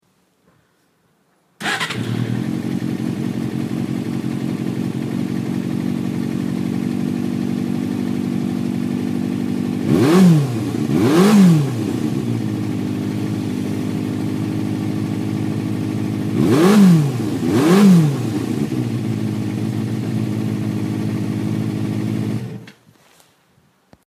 ノーマルとサイクロンの音色の違いを体感してください。
アクセルを開けた時の音が、ノーマルは細く飛び出すような感じでサイクロンは低音で横に広がる感じに僕は思います。